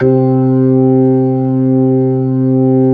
Index of /90_sSampleCDs/AKAI S-Series CD-ROM Sound Library VOL-8/SET#5 ORGAN
HAMMOND   9.wav